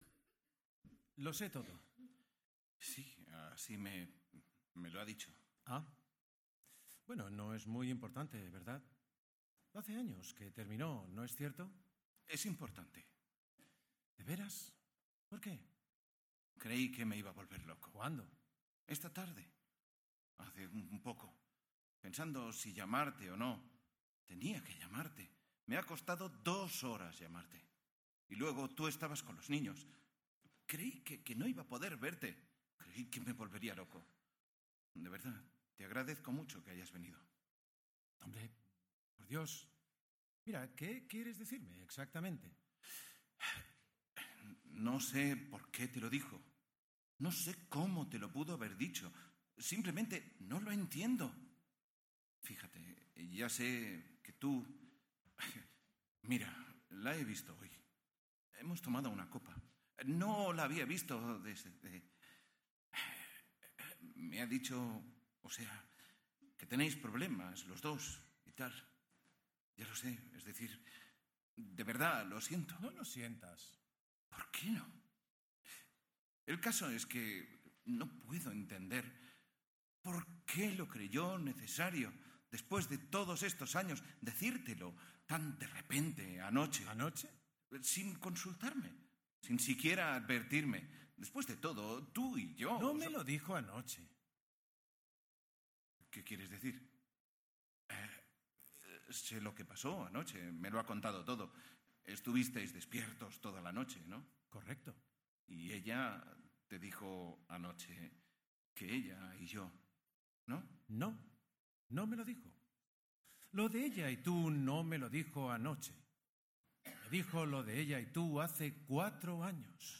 “Traición” formato MP3 audio(4,20 MB) de Harold Pinter.  El jurado ha destacado “cómo el grupo conquista al oyente con una ambientación sonora muy conseguida y un sonido de voces equilibrado, un ritmo de lectura muy acertado y un exquisito trabajo de descomposición de la estructura literaria; la rotura de texto es impecable, el nivel interpretativo es bueno, incluso un poco más que bueno,